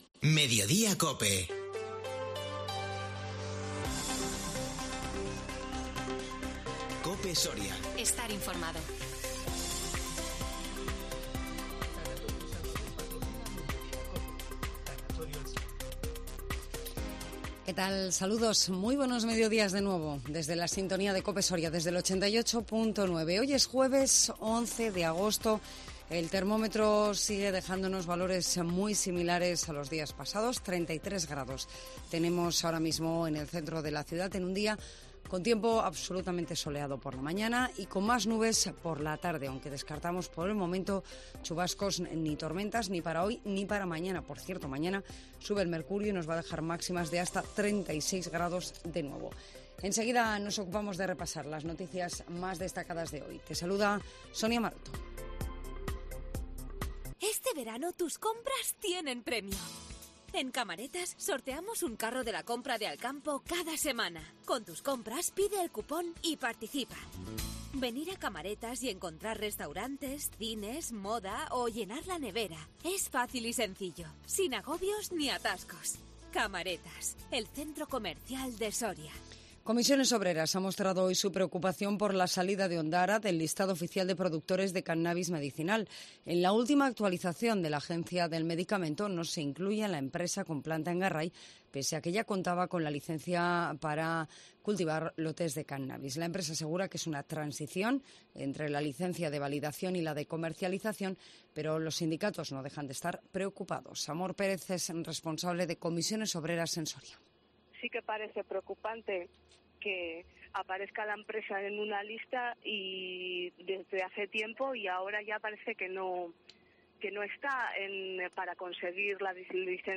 INFORMATIVO MEDIODÍA COPE SORIA 11 AGOSTO 2022